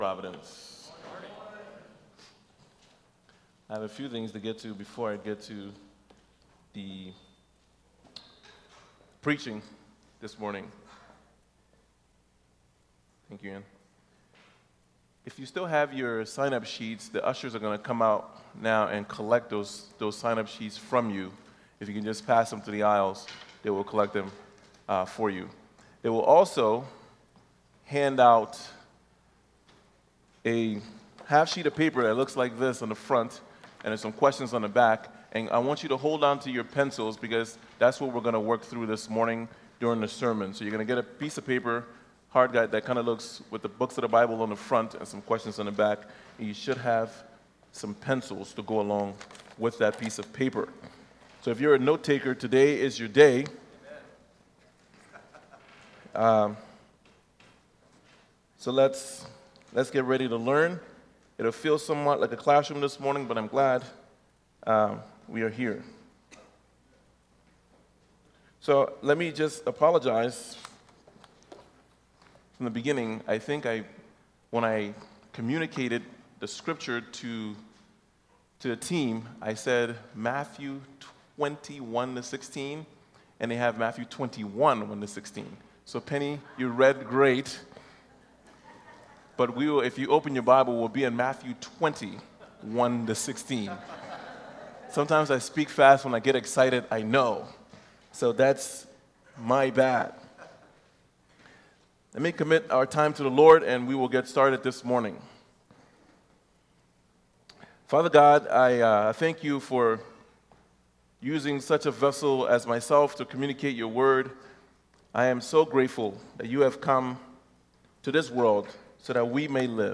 1.27.19-sermon.mp3